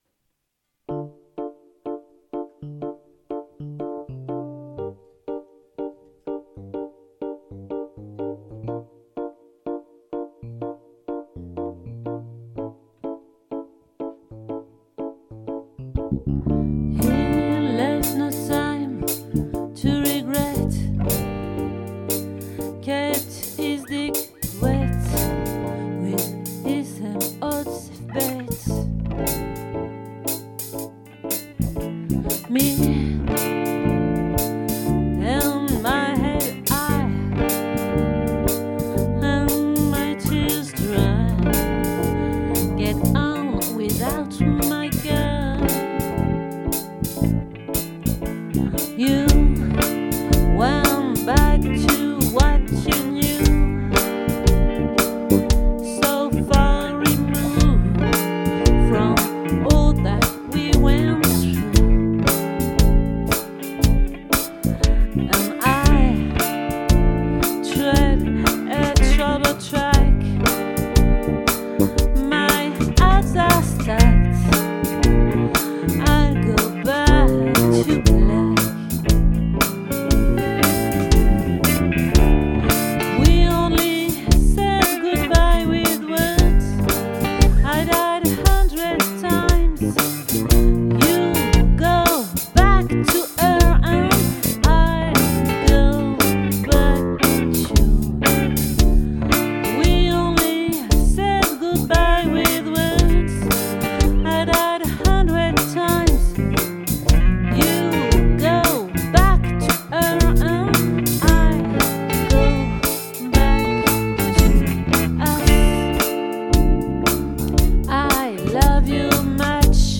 🏠 Accueil Repetitions Records_2022_11_09